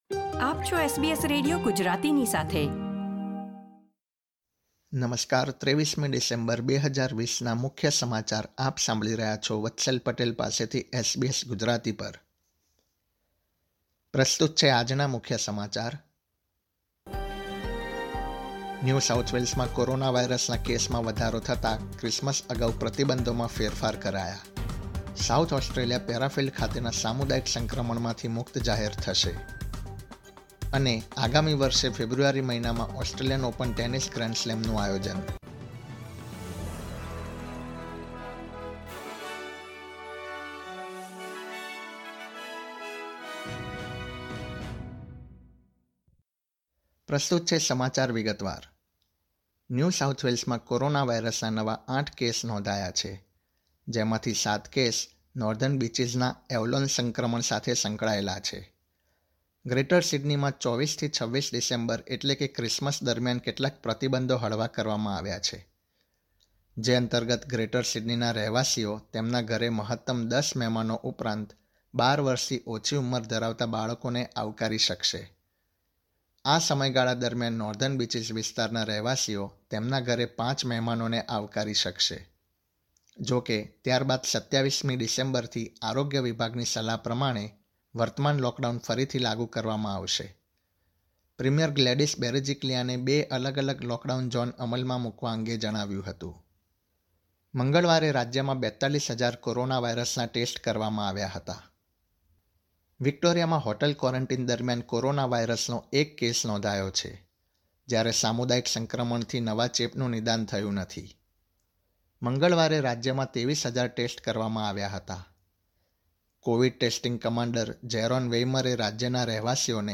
SBS Gujarati News Bulletin 23 December 2020